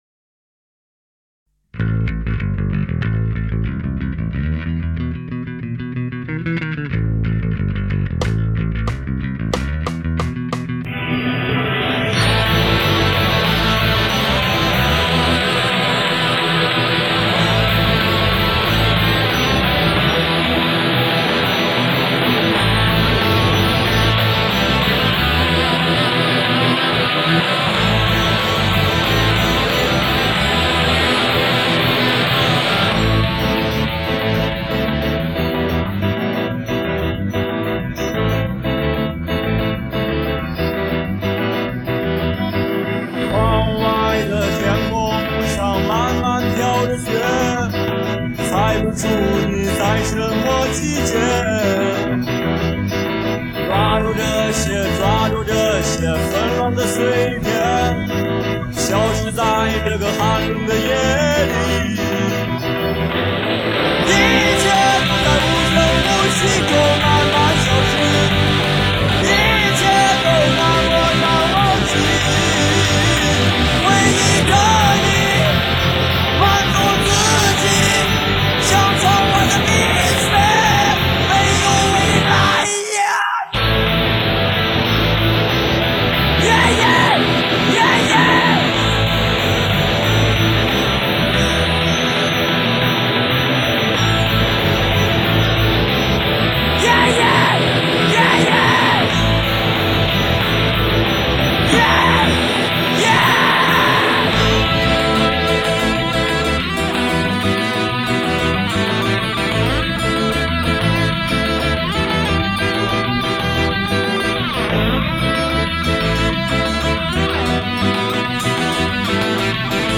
无鼓伴奏